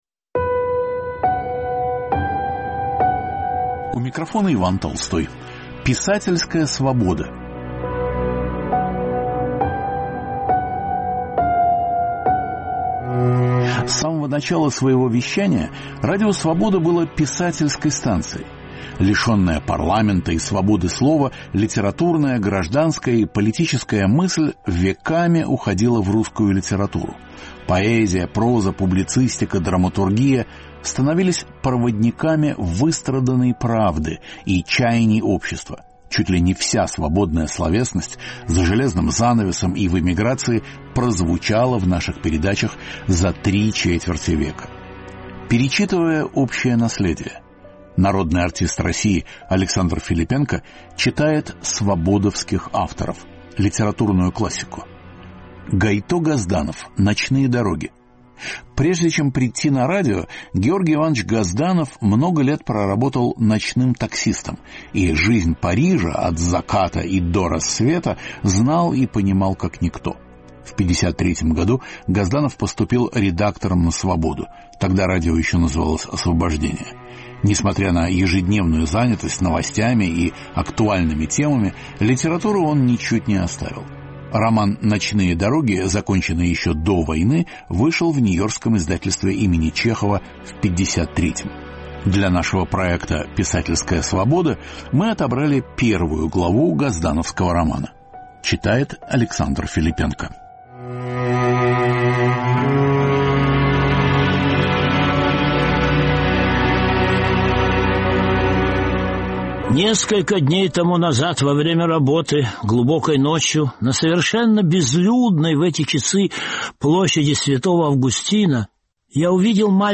Писательская свобода. Александр Филиппенко читает фрагмент из романа Гайто Газданова «Ночные дороги»